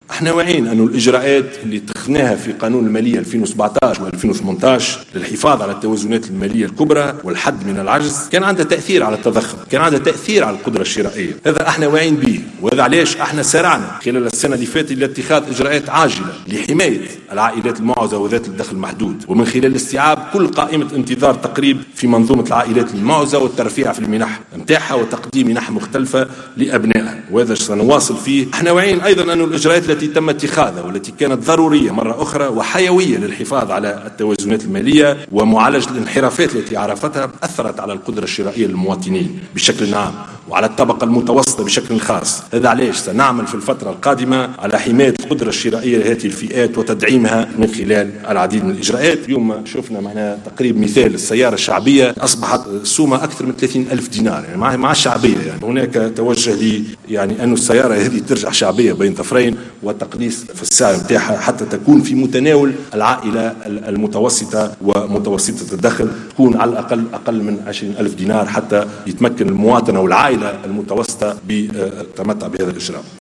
أكد رئيس الحكومة يوسف الشاهد اليوم خلال افتتاح الندوة الوطنية حول التوجهات الاقتصادية و الاجتماعية لمشروع قانون المالية لسنة 2019 أنه سيتم العمل على حماية القدرة الشرائية للمواطن و خاصة الفئات الضعيفة و المتوسطة وتدعيمها من خلال العديد من الإجراءات.